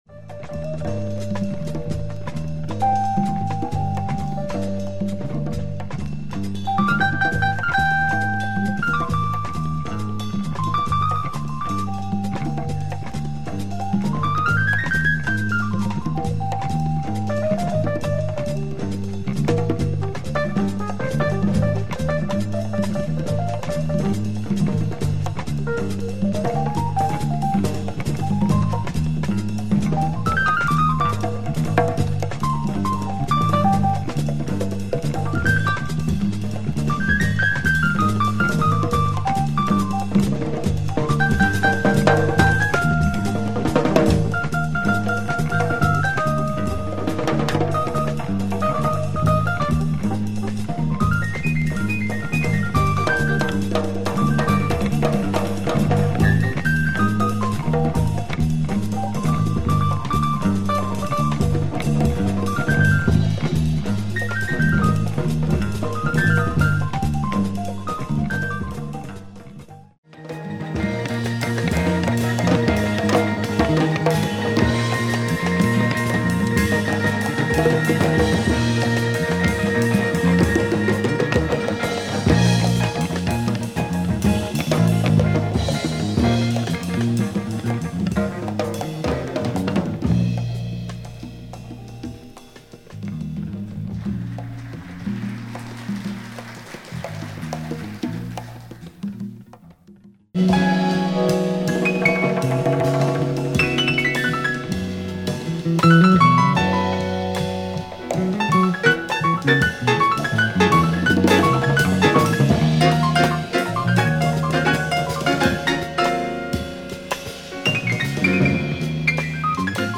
jazz
groovy tunes